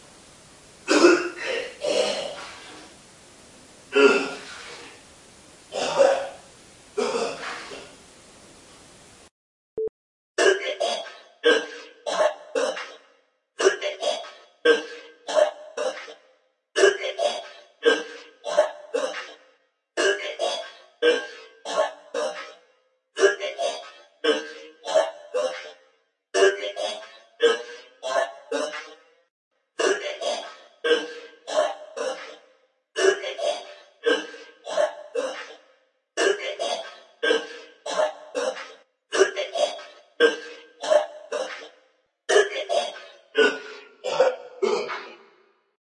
Descarga de Sonidos mp3 Gratis: vomito.
descargar sonido mp3 vomito
vomito-.mp3